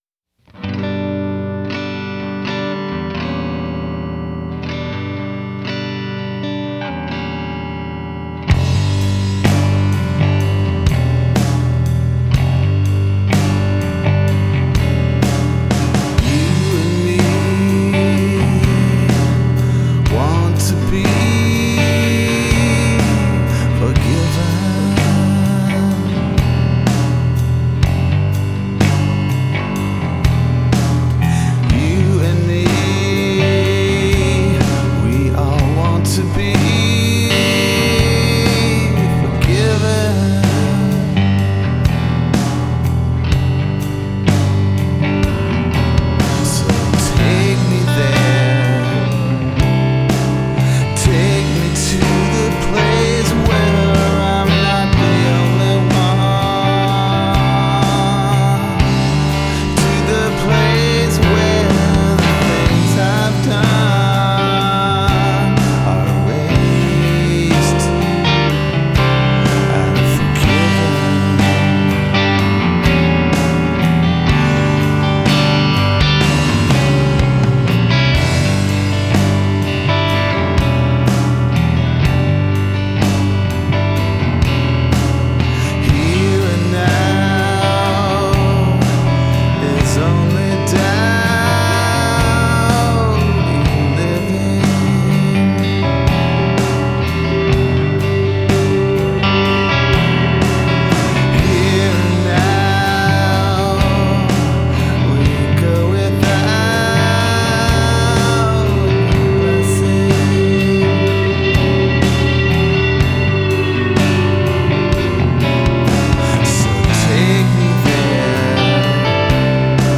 Key G
Tempo 62 BPM